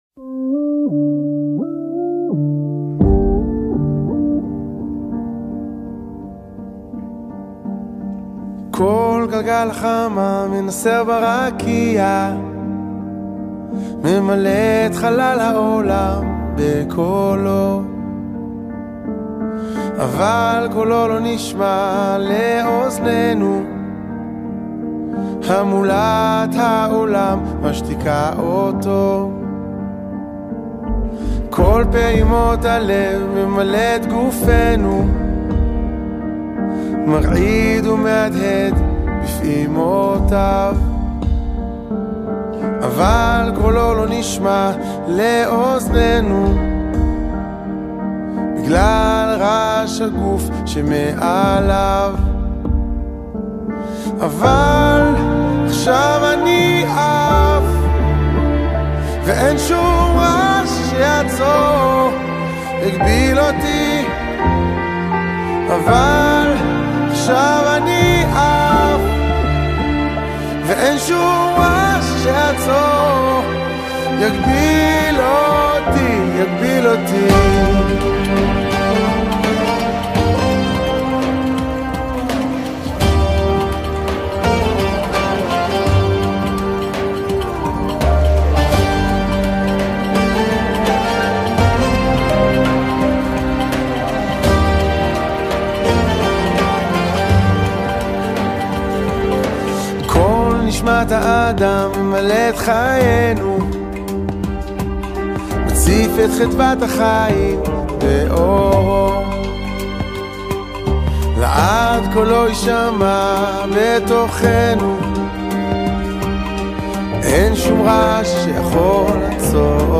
שיר מקסים, אופטימי ומרגש.